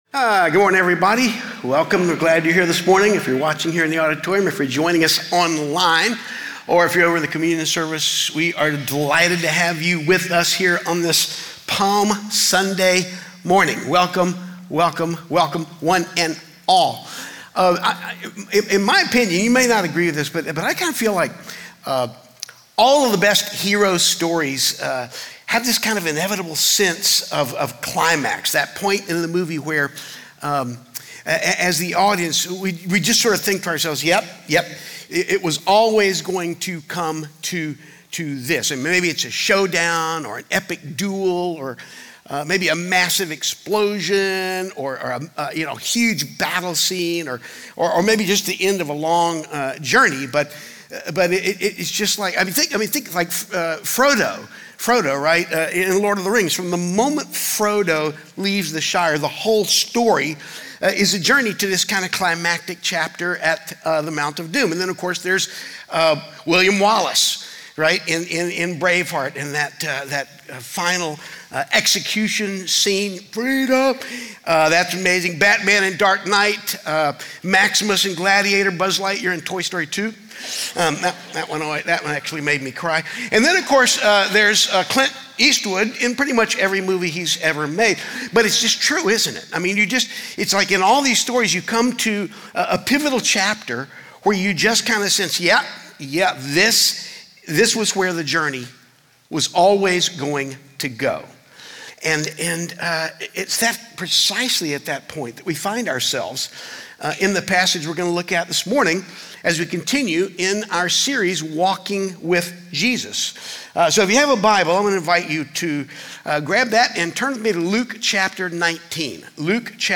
Faithbridge Sermons Triumphal Entry Mar 29 2026 | 00:34:13 1x 00:00 / 00:34:13 Subscribe Share Apple Podcasts Spotify Overcast RSS Feed Share Link Embed